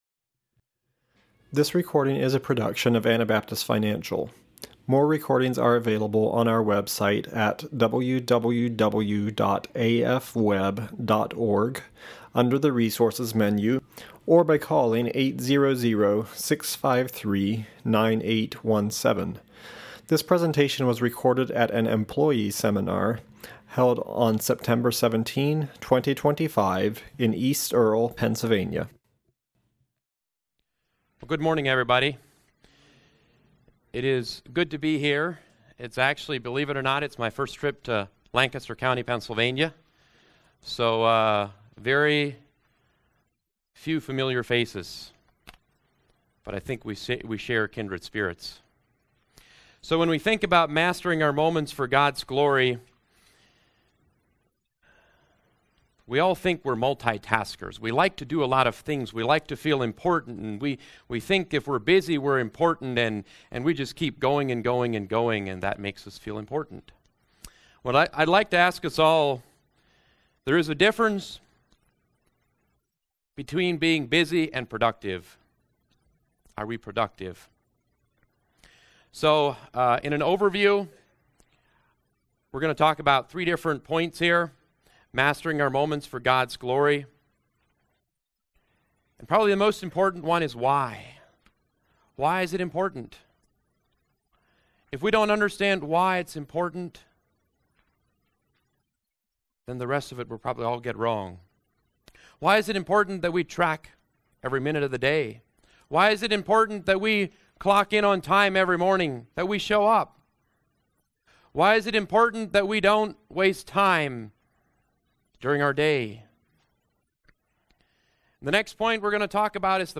Pennsylvania Employee Seminar 2025